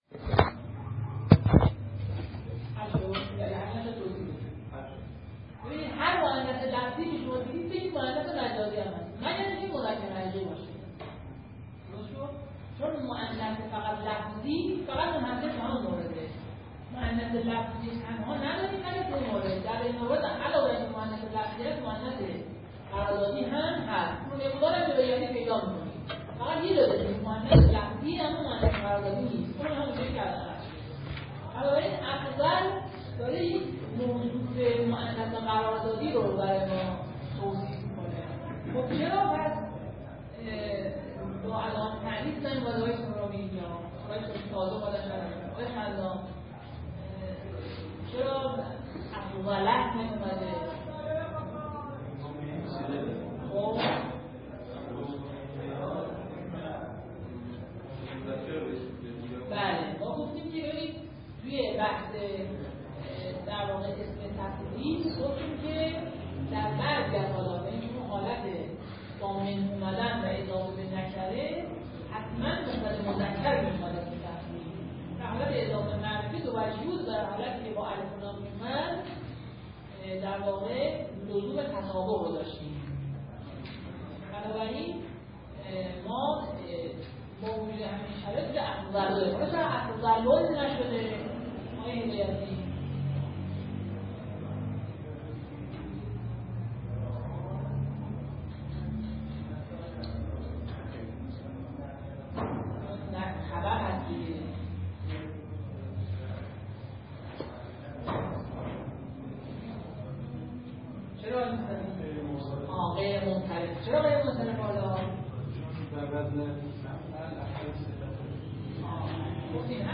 شرح صوتی مونث و مذکر و نمودار جلسه اول.mp3